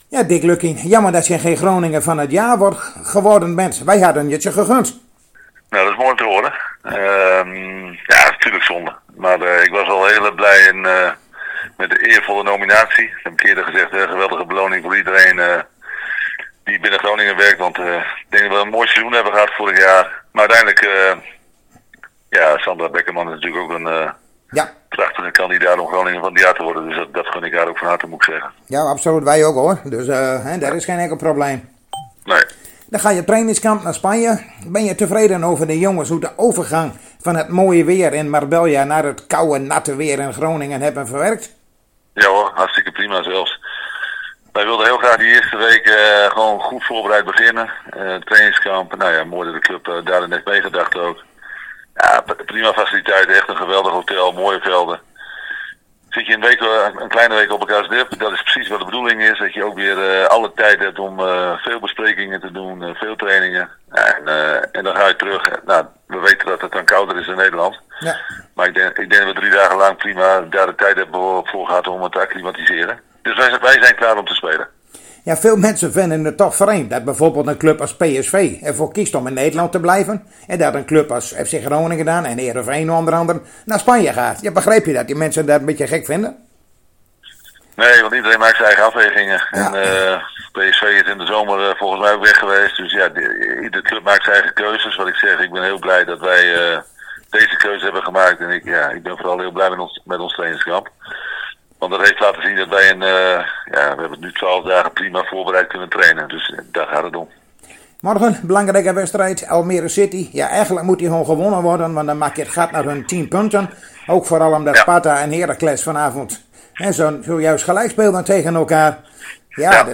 Zojuist spraken wij weer met Dick Lukkien in aanloop naar de wedstrijd van zondag tegen Almere City. Hij vertelt hierin o.a. over de blessure's bij de FC.